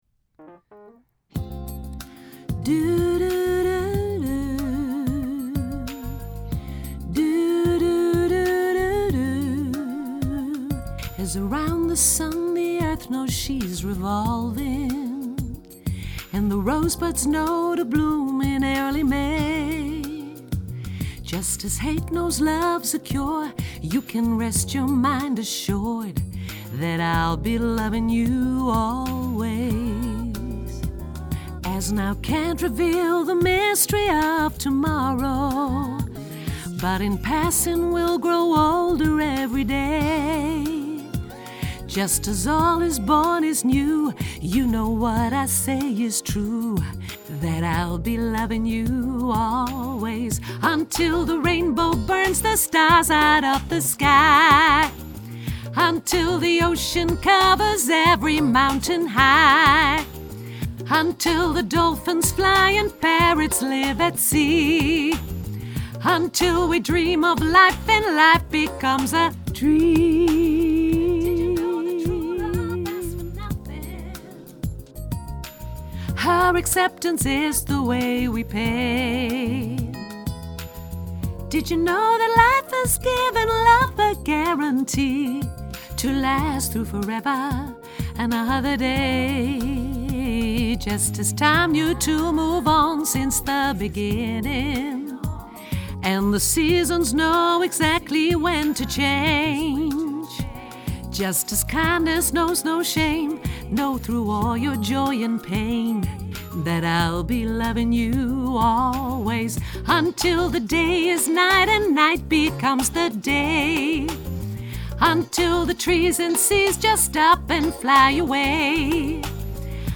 alt laag